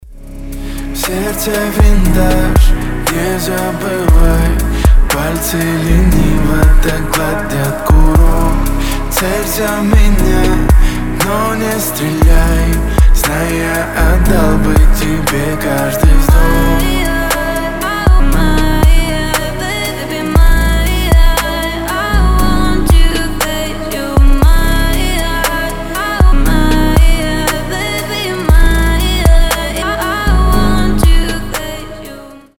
• Качество: 320, Stereo
красивые
лирика
дуэт
медленные